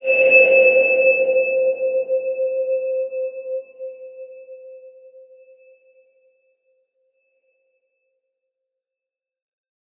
X_BasicBells-C3-pp.wav